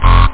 303-analog.mp3